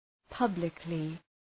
Προφορά
{‘pʌblıklı}